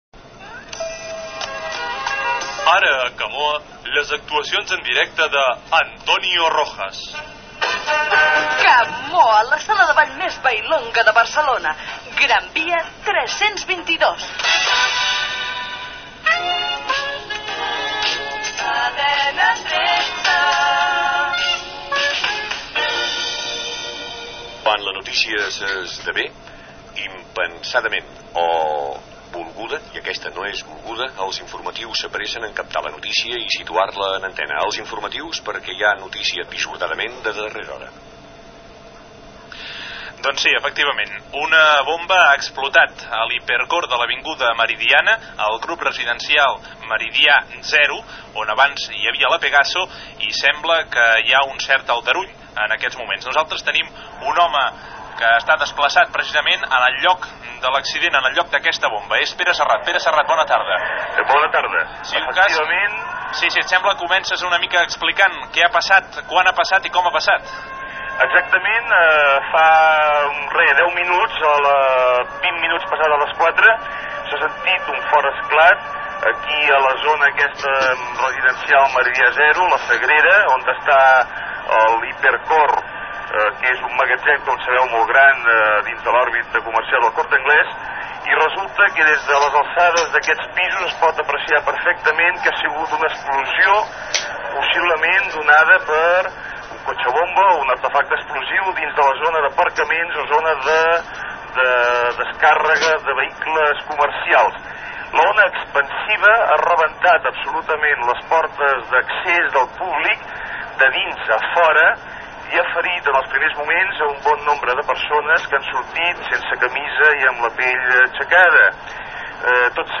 Publicitat, indicatiu de la cadena, s'interrop el programa “Bon vent i barca nova” per donar pas als serveis informatius de Cadena 13. Connexió amb la Meridiana de Barcelona per donar la primera informació de l'atemptat a Hipercor
Informatiu
FM